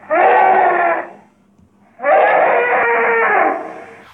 File:Roar - Styracosaurus Spider Pit.ogg
Roar_-_Styracosaurus_Spider_Pit.ogg